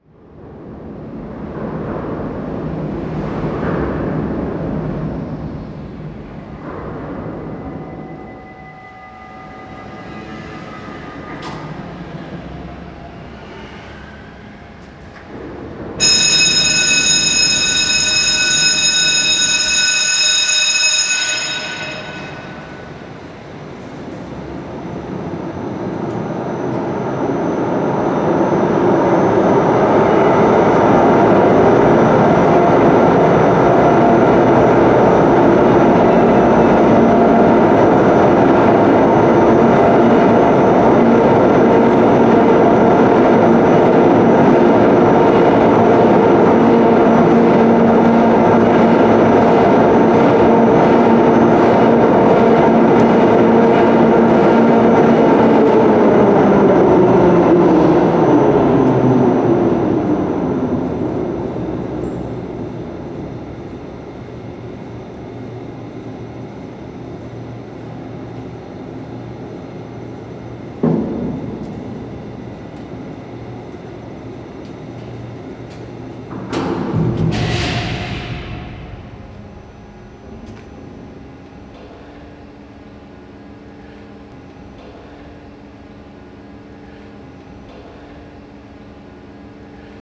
Device: Zoom h4n Mono link mode Applied low shelf filter cutoff 100hz -12db in post Maskiner Folk / Djur Trafik